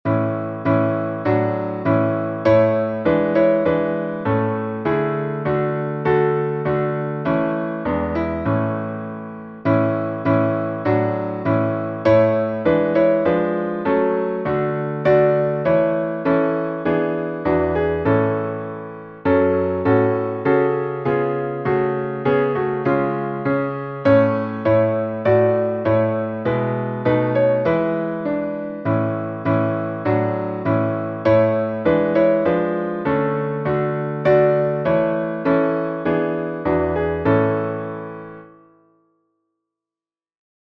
salmo_148B_instrumental.mp3